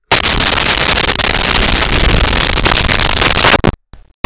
Creature Snarl 01
Creature Snarl 01.wav